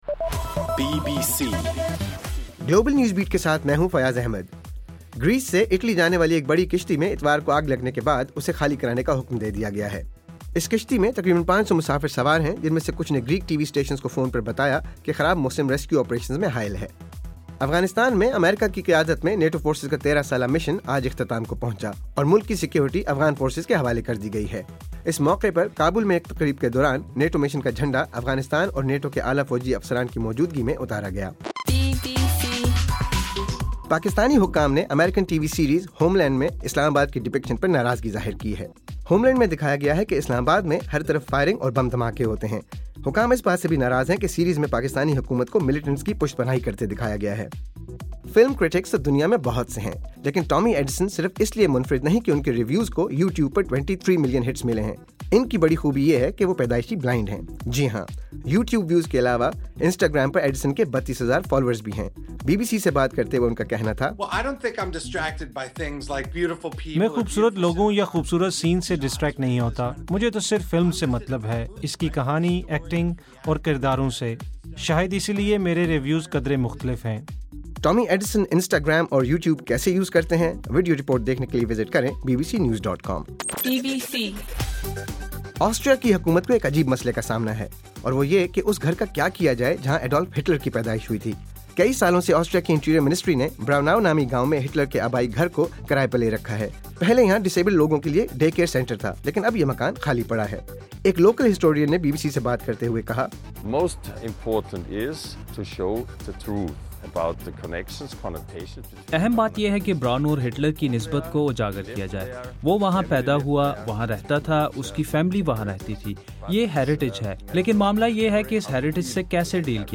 دسمبر 28: رات 9 بجے کا گلوبل نیوز بیٹ بُلیٹن